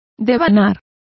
Complete with pronunciation of the translation of unwinding.